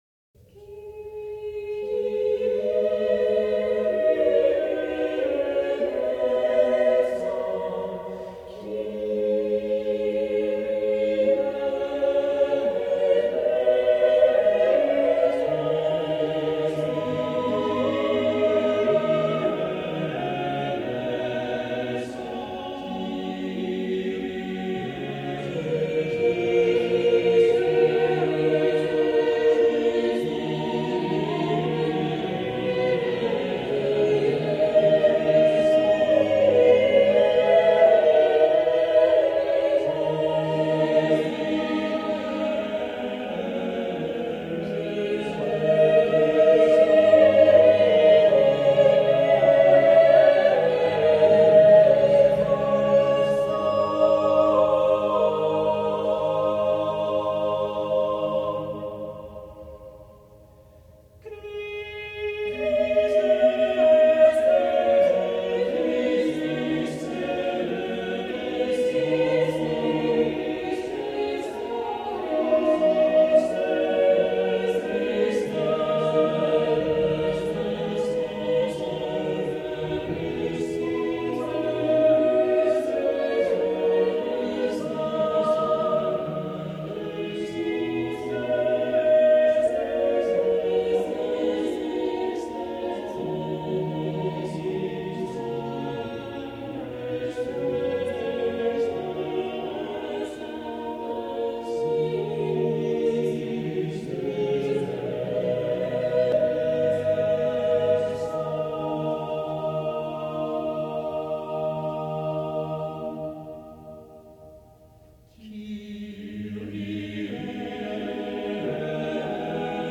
Vocal Ensemble